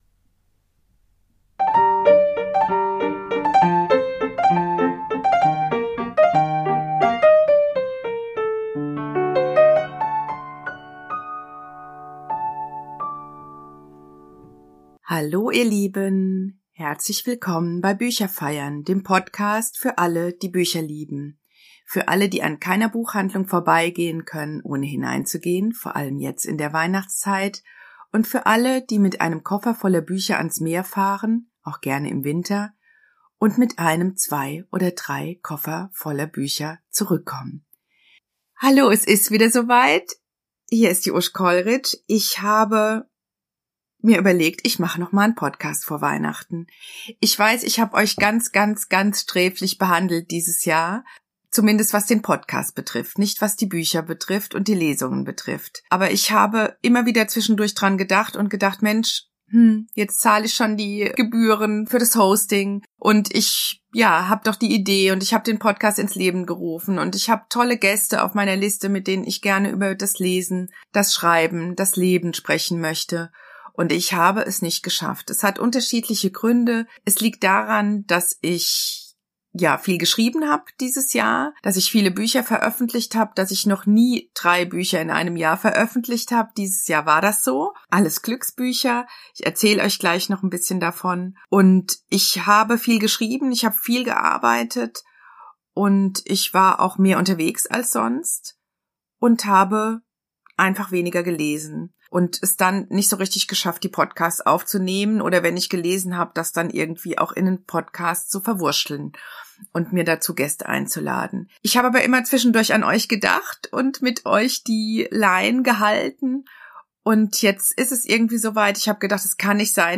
So wird diese Folge zu einer kunterbunten Mischung aus Bücherabend und Lesung.